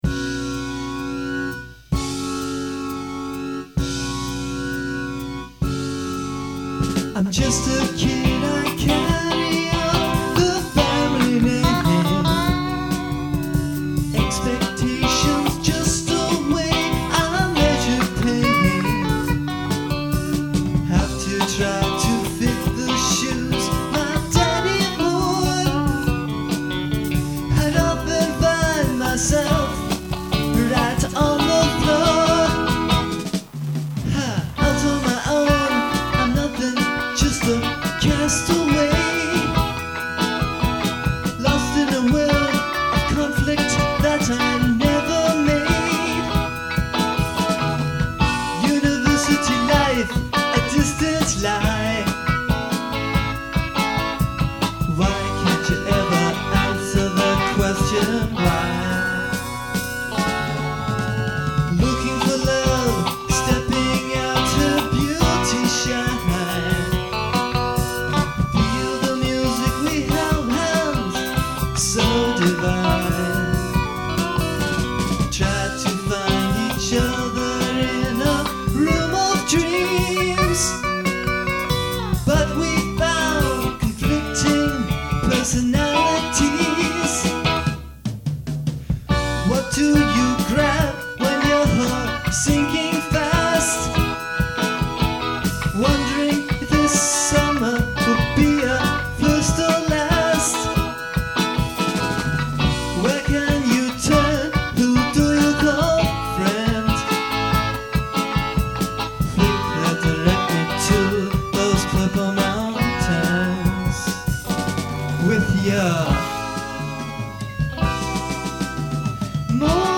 During that weekend the group recorded eleven songs on the Tascam 22-4, 4-track, reel-to-reel, tape recorder.